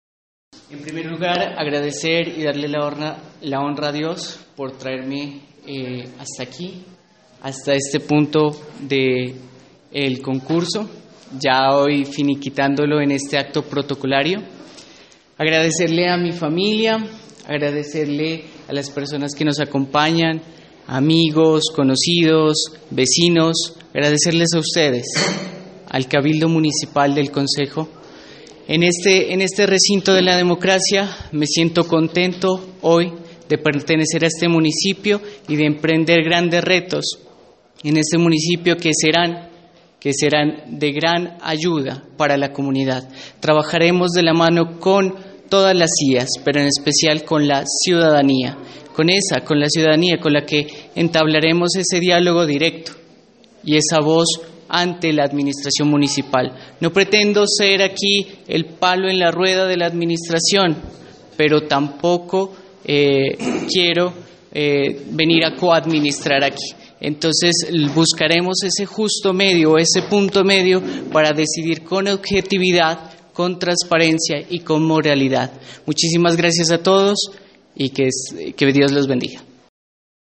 El abogado Christian Esteban Zambrano Ágreda tomó posesión del cargo de personero municipal de Sandoná este sábado en horas de la mañana en el salón del Concejo Municipal, para el período comprendido entre el 1º de marzo del presente año hasta el 29 de febrero de 2024.
El acto continuó con la firma del acta de posesión y las palabras del nuevo personero municipal.
Posesión-personero-Cristian-Zambrano.mp3